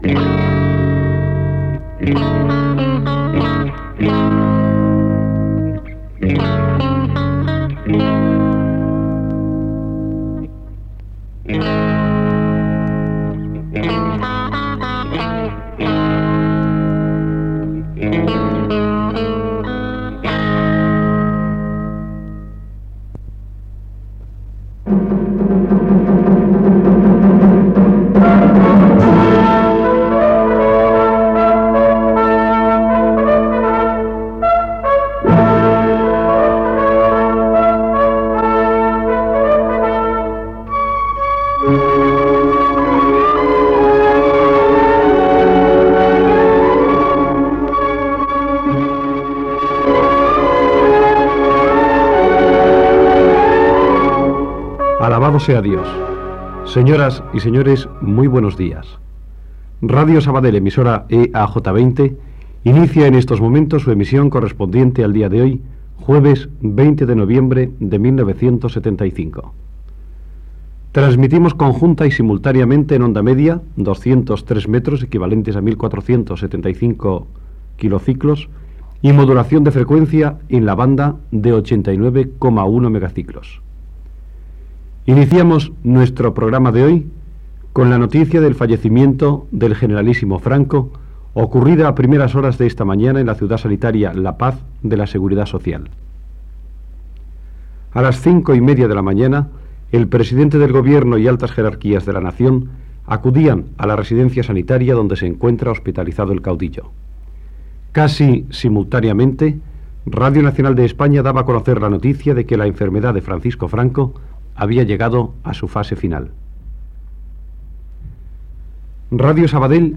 Tema musical, identificació, comunicat oficial de la mort de Francisco Franco llegit pel ministre d'Informació i Turisme, tema musical, identificació i connexió amb RNE.
Informatiu